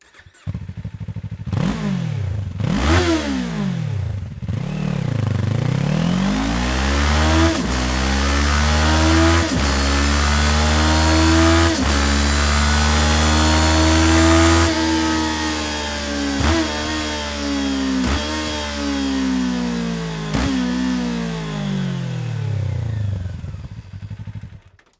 サウンド(Stock exhaust)はこちら